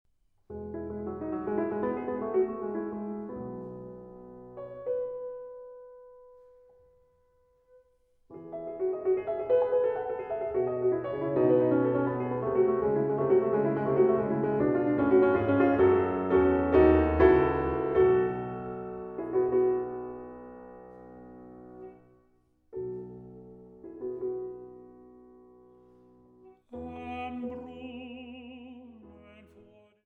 Bariton
Klavier